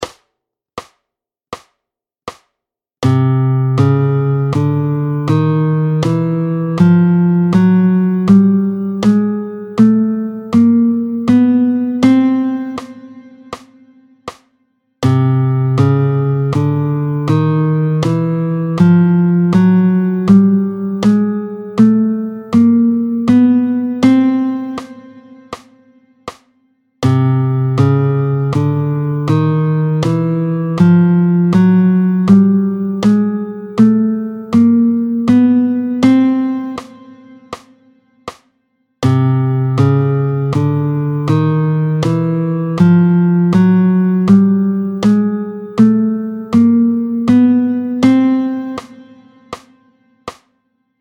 Luth
09-01 La gamme chromatique, tempo 80